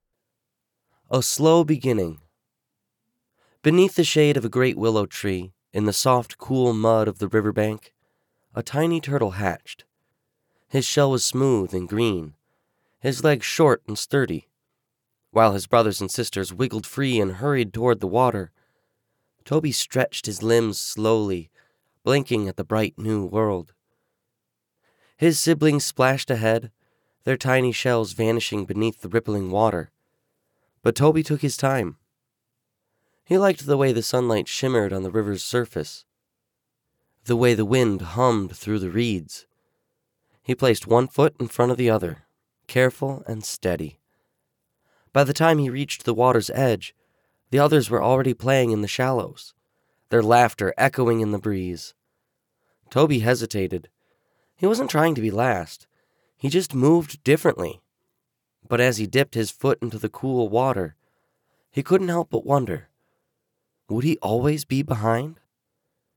Male
Yng Adult (18-29), Adult (30-50)
Audiobooks
Children'S Stories